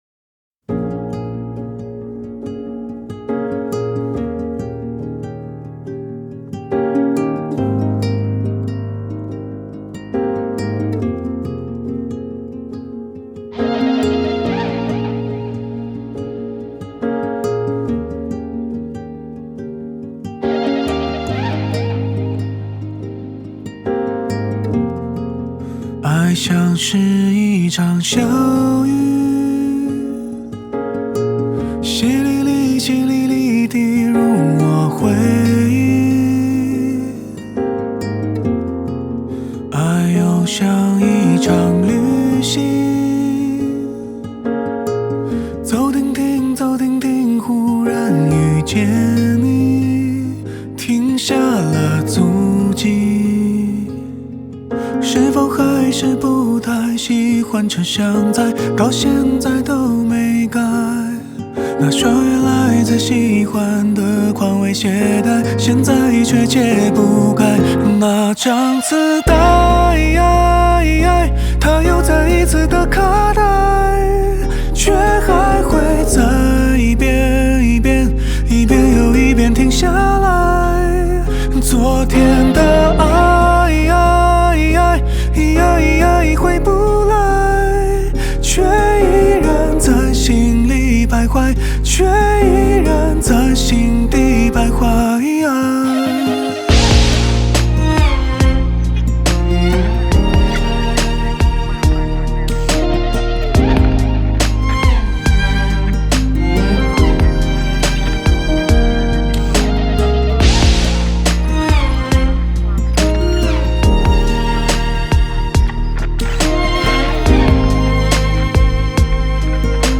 Popular Chinese Song